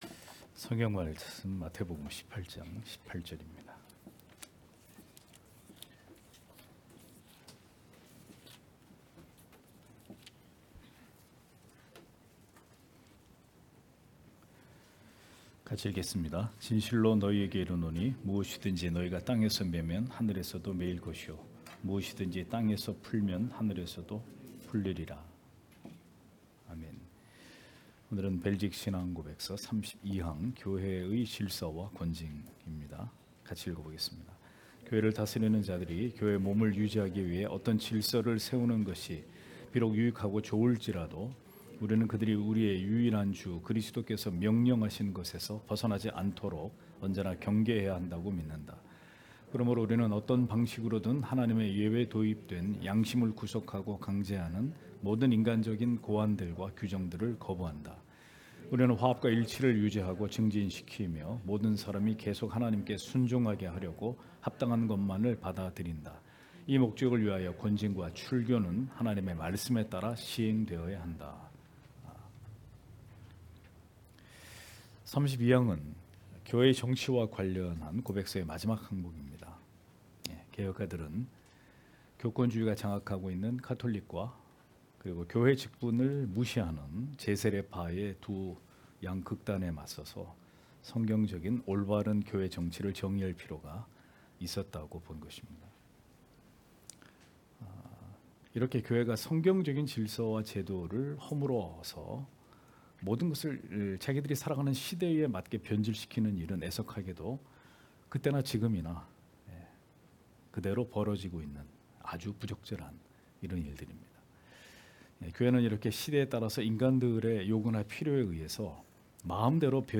주일오후예배 - [벨직 신앙고백서 해설 37] 제32항 교회의 질서와 권징 (마 18장18절)